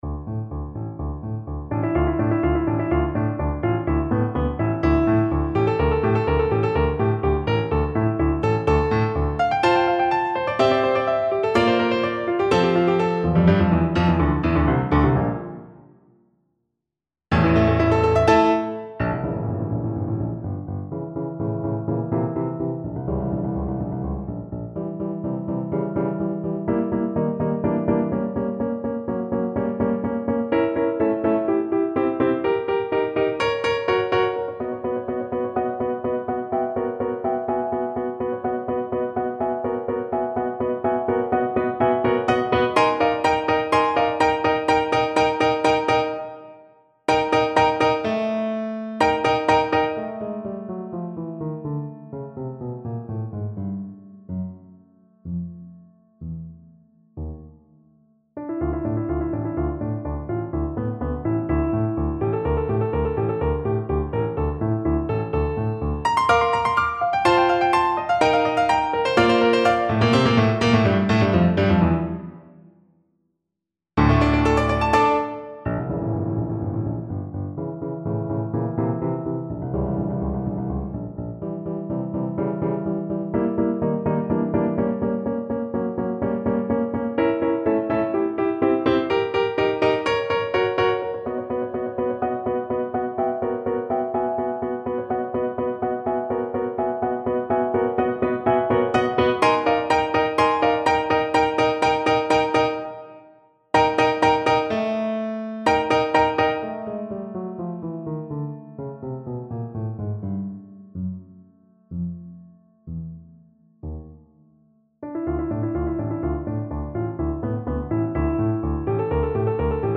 Play (or use space bar on your keyboard) Pause Music Playalong - Piano Accompaniment Playalong Band Accompaniment not yet available transpose reset tempo print settings full screen
Trombone
D minor (Sounding Pitch) (View more D minor Music for Trombone )
2/2 (View more 2/2 Music)
Allegro molto = 176 (View more music marked Allegro)
Classical (View more Classical Trombone Music)